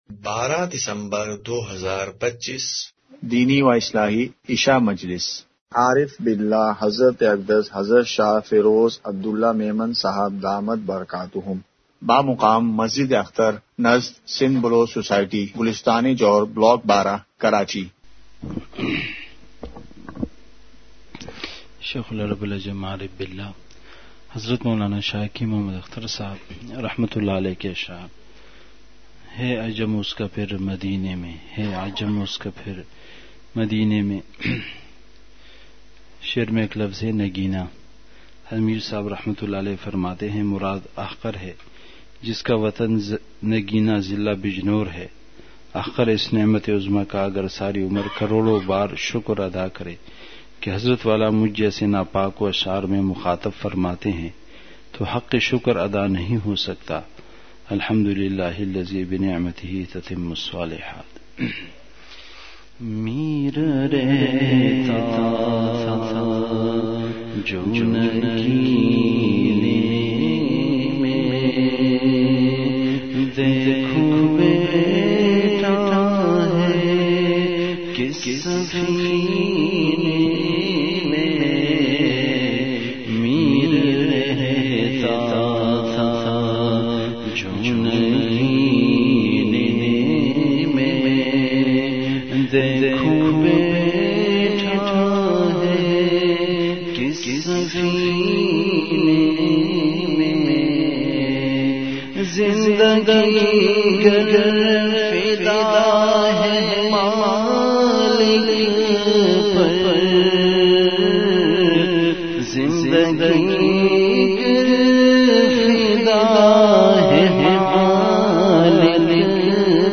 اصلاحی مجلس کی جھلکیاں *مقام:مسجد اختر نزد سندھ بلوچ سوسائٹی گلستانِ جوہر کراچی*